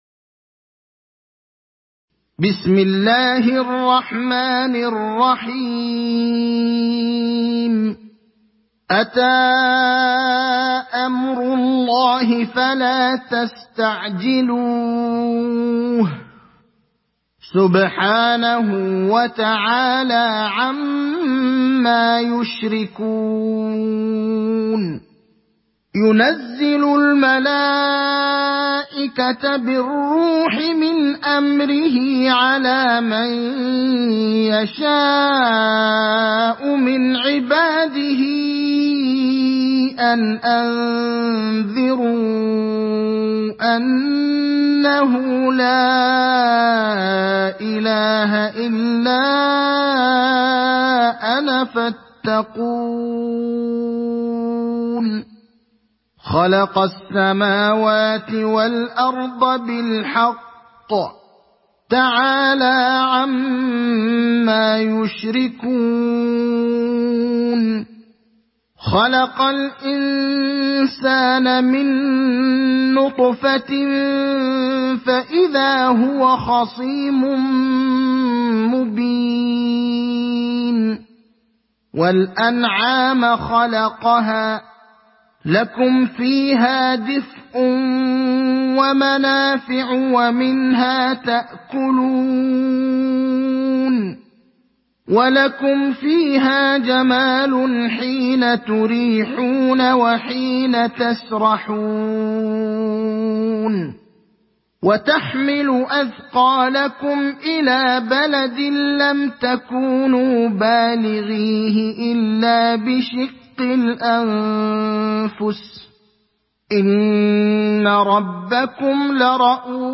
Sourate An Nahl Télécharger mp3 Ibrahim Al Akhdar Riwayat Hafs an Assim, Téléchargez le Coran et écoutez les liens directs complets mp3